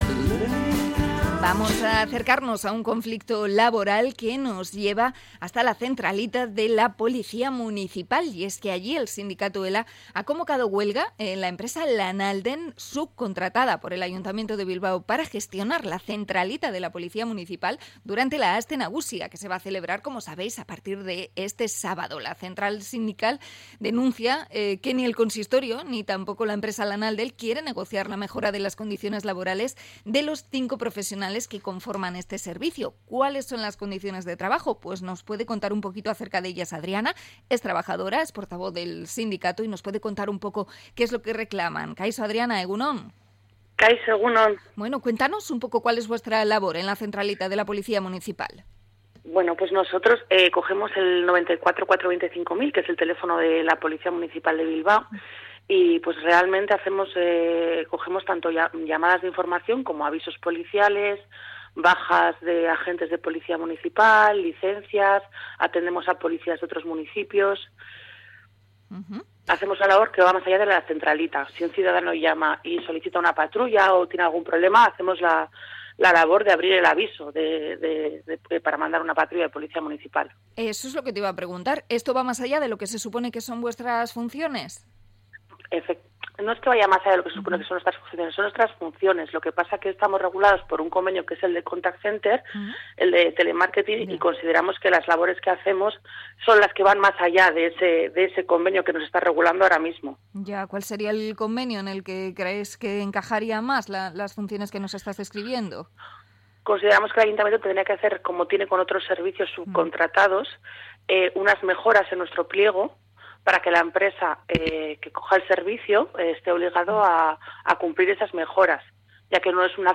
Entrevista a trabajadora de la centralita de la policía de Bilbao